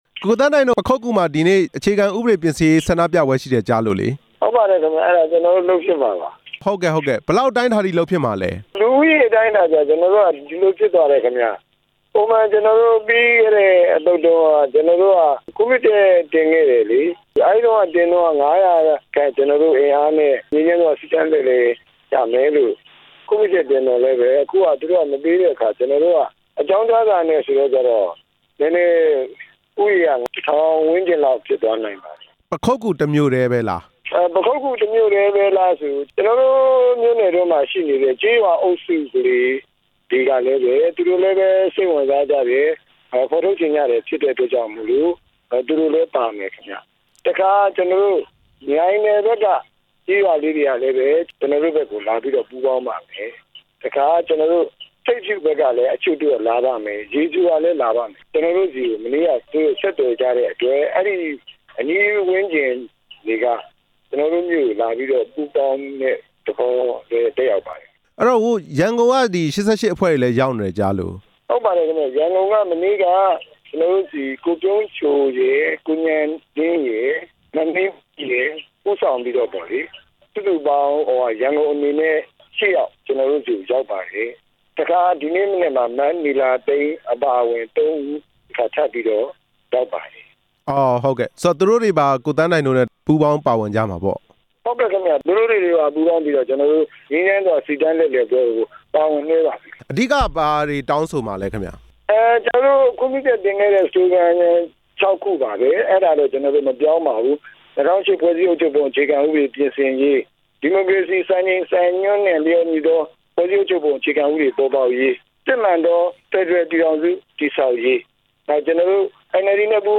မေးမြန်းချက်